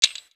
Whe_Perc8.wav